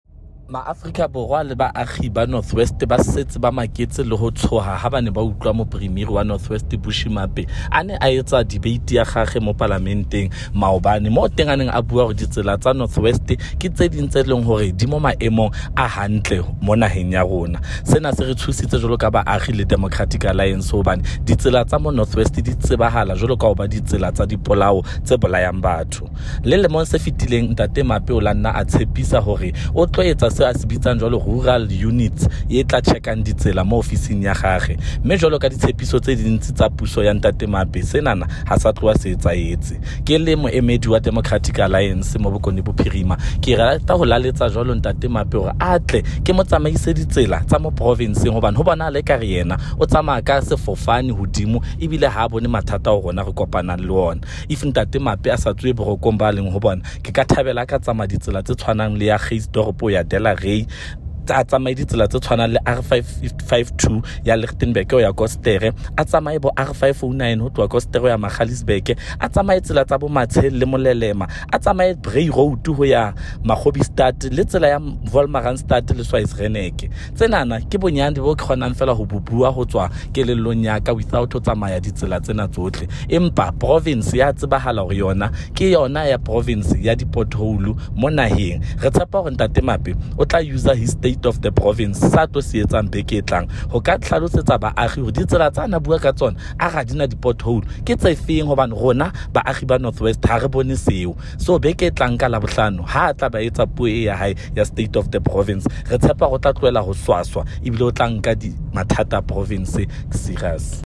Note to Broadcasters: Find linked soundbites in
Sesotho by Freddy Sonakile MPL